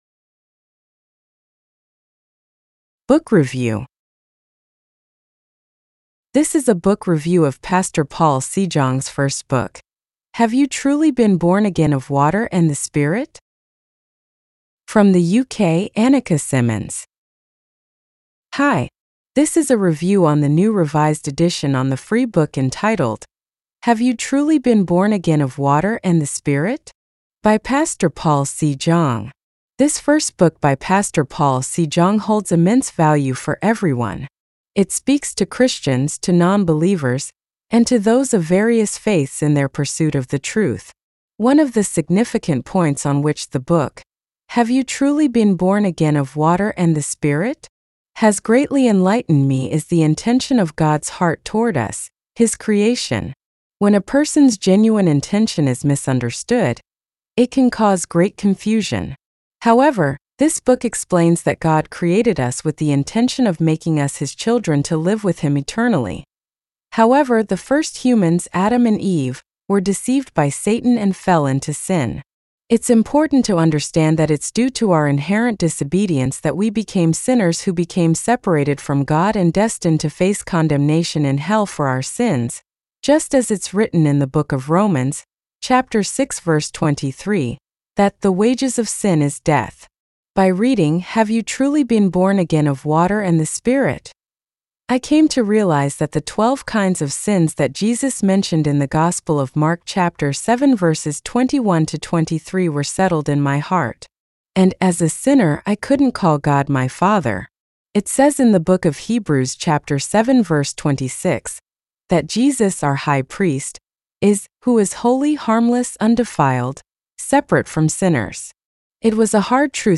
12. Book Review